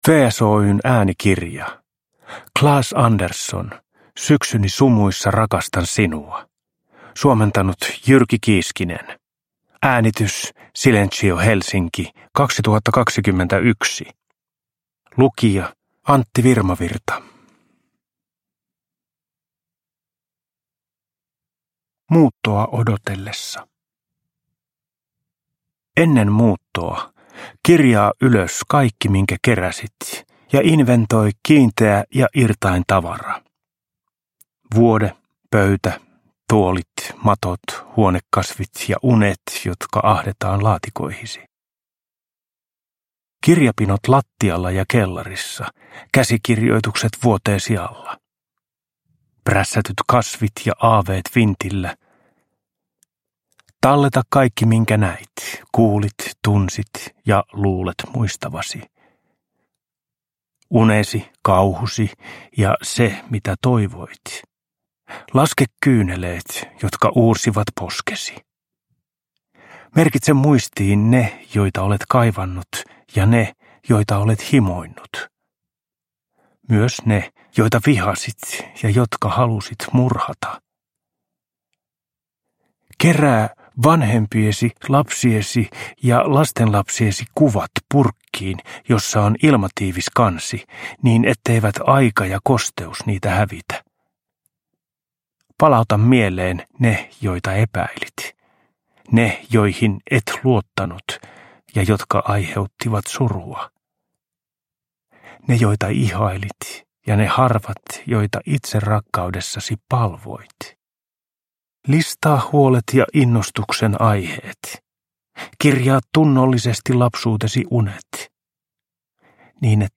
Syksyni sumuissa rakastan sinua – Ljudbok – Laddas ner
Uppläsare: Antti Virmavirta